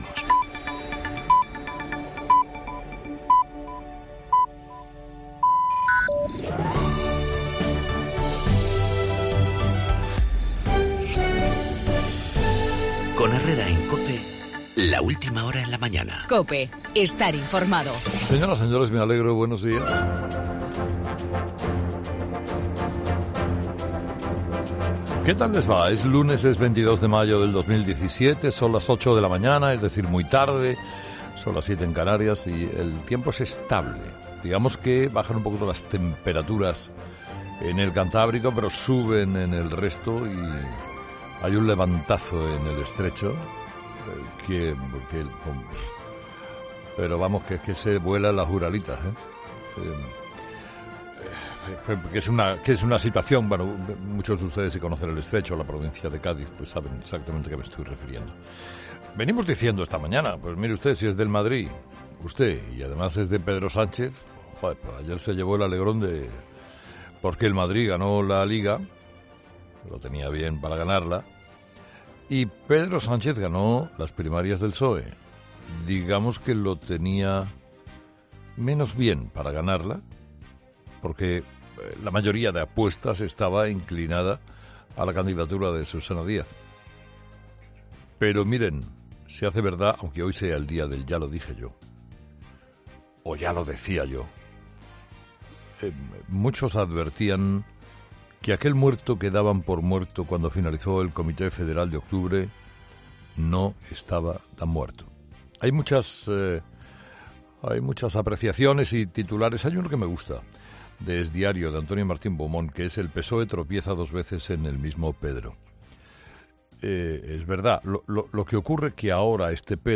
AUDIO: La victoria de Pedro Sánchez y el plan de independencia de Cataluña, en el monólogo de Carlos Herrera a las 8 de la mañana.